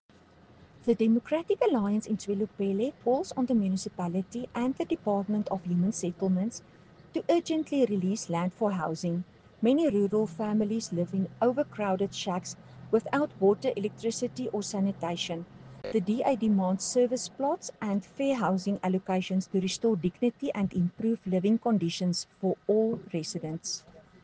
Afrikaans soundbites by Cllr Estelle Pretorius and